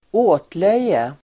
Uttal: [²'å:tlöj:e]